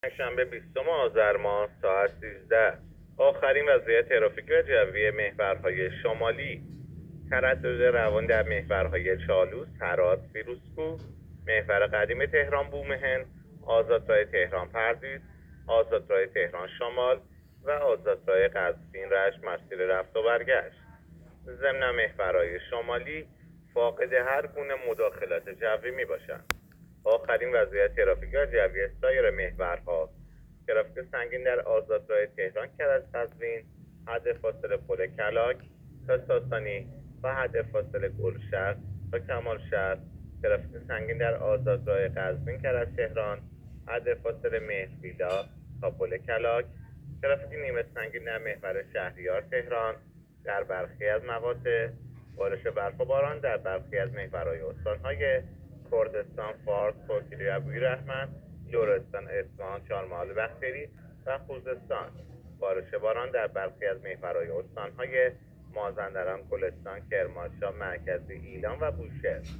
گزارش رادیو اینترنتی از آخرین وضعیت ترافیکی جاده‌ها ساعت ۱۳ بیستم آذر؛